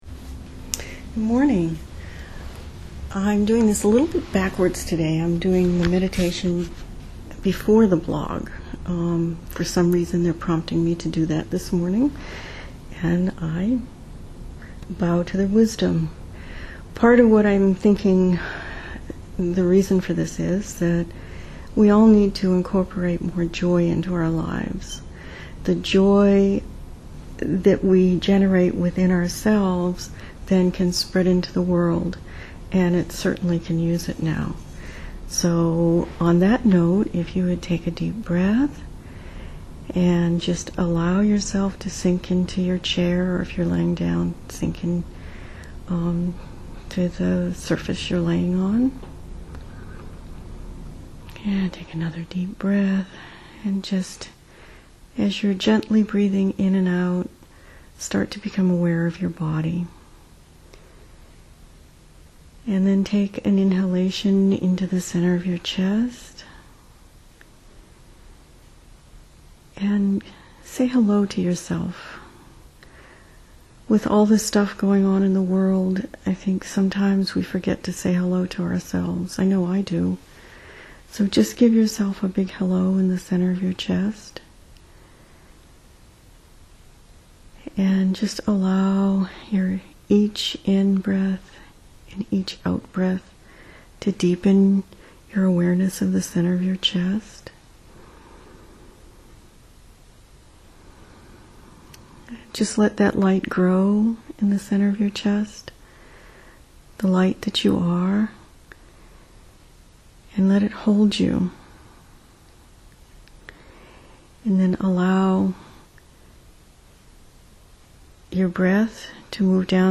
This meditation might be fun to do!